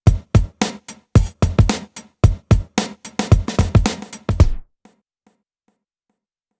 Grid, Spring and Delay keep resounding, but Mother doesn’t. It might be an effect you’re looking for, but 99% of the time I’d like my reverb to keep reverberating after punching in.
Delay.mp3